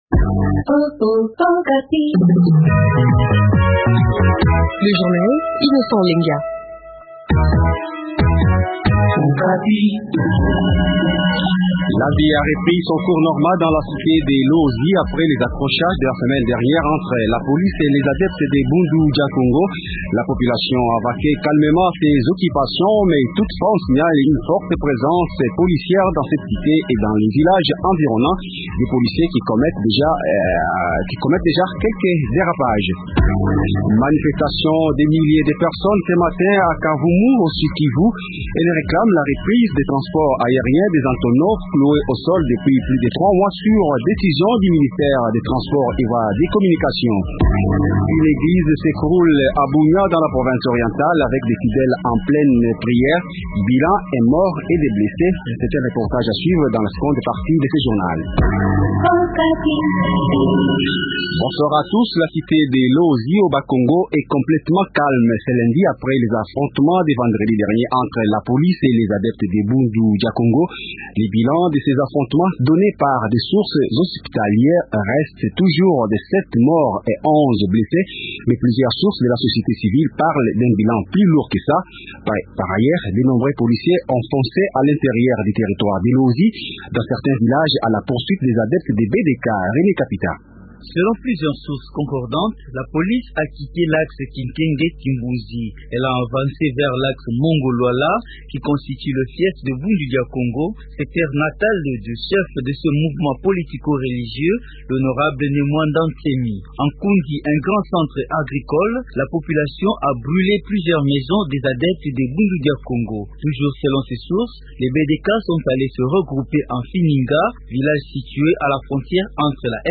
C’est un reportage à suivre dans la seconde partie de ce journal.